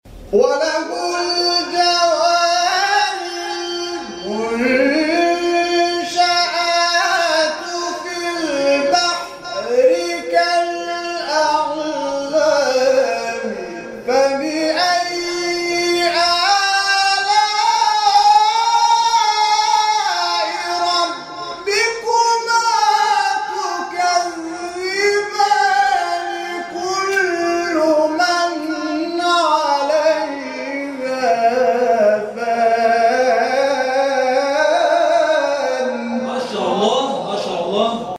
شبکه اجتماعی: فرازهای صوتی از تلاوت قاریان ممتاز کشور را می‌شنوید.
سور الرحمن در مقام رست